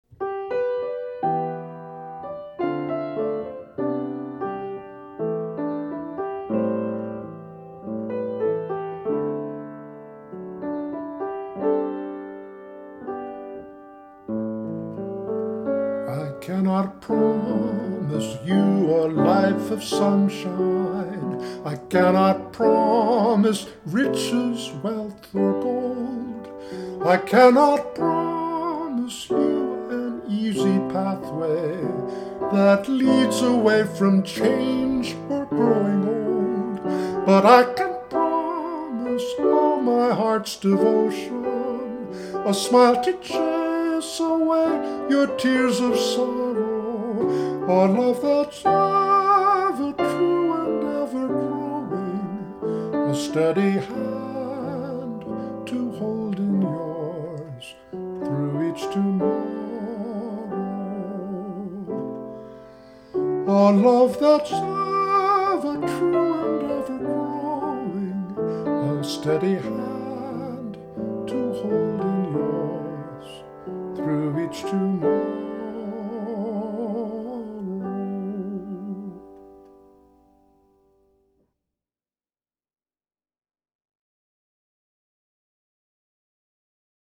Wedding song, author unknown